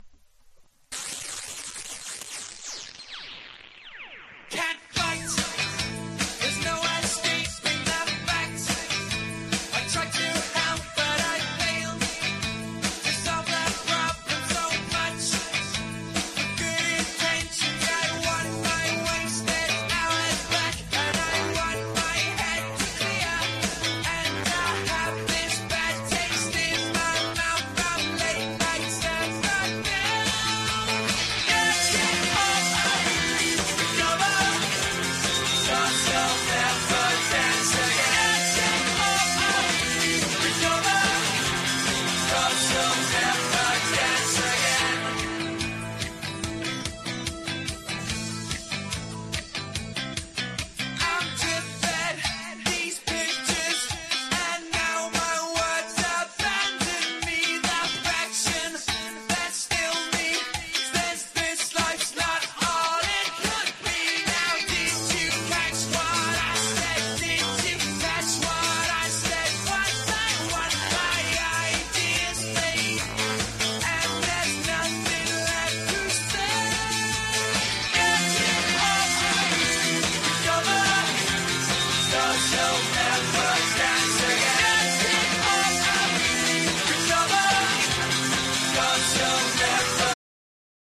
1. 90'S ROCK >
当代随一のヤング・メロディアス・バースト・バンド！！